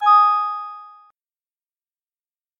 Звуки ввода пароля